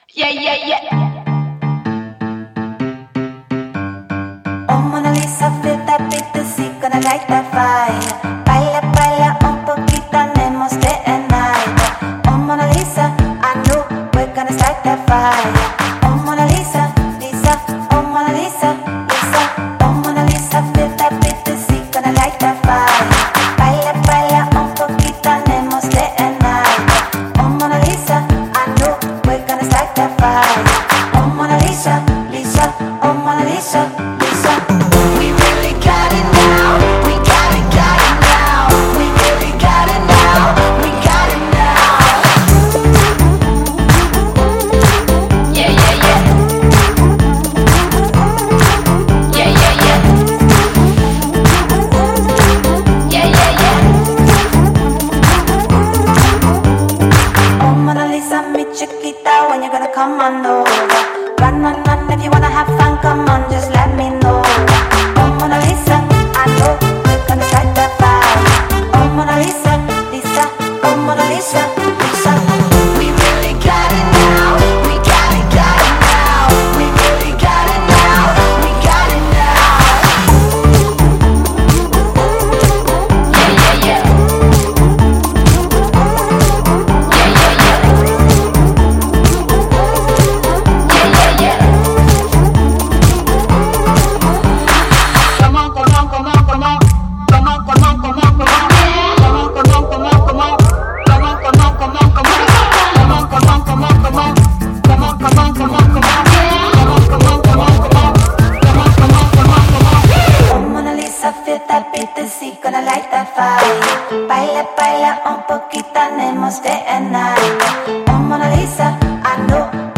Зарубежная музыка, Популярная музыка